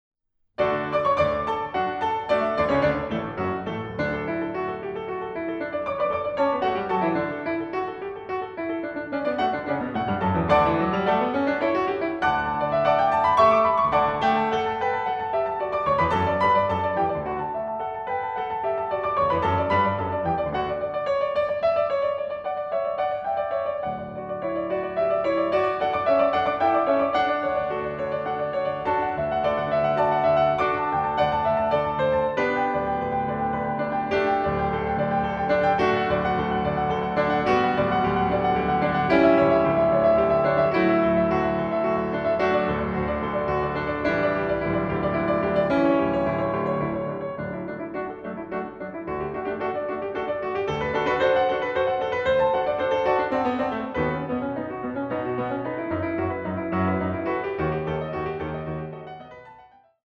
Klavier, Cembalo